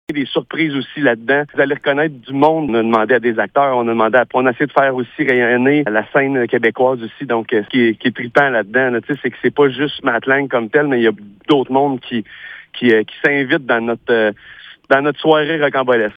Vous pouvez accéder à l’entrevue complète dans la section Entrevues sur notre site Web.